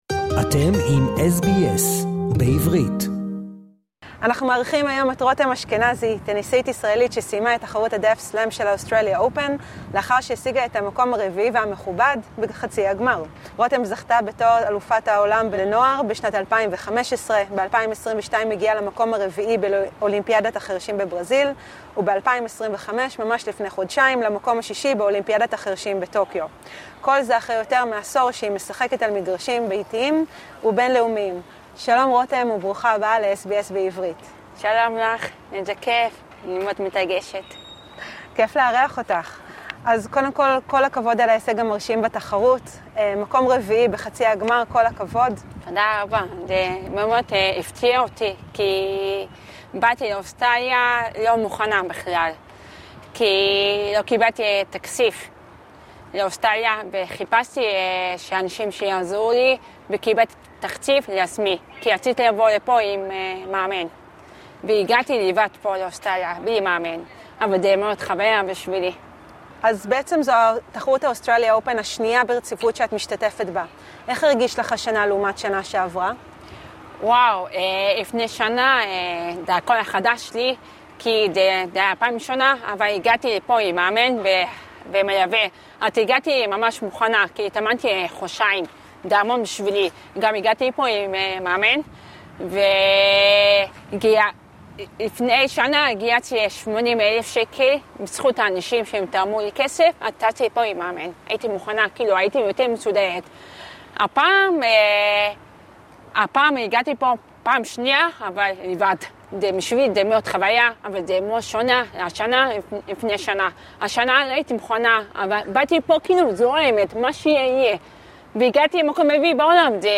טניסאית ישראלית שהגיעה לתחרות הטניס לחירשים של ה-Australia Open בראיון ל-SBS